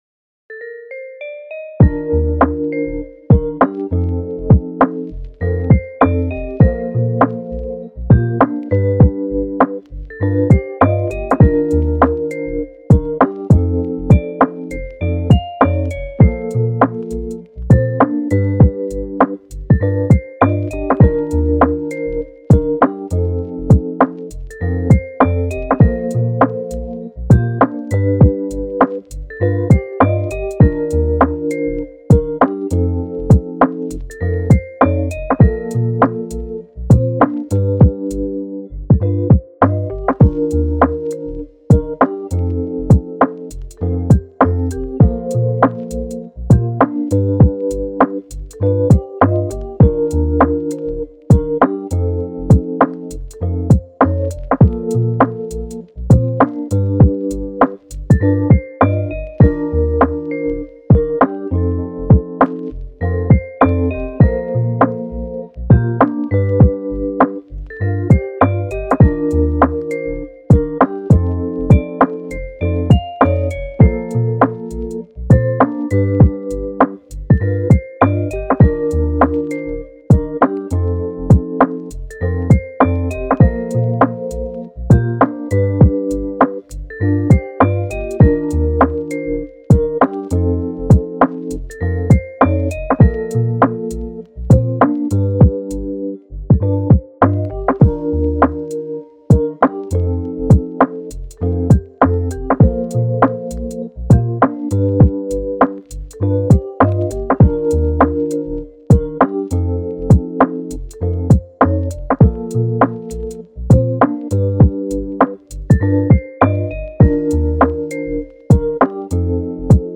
カフェミュージック
チル・穏やか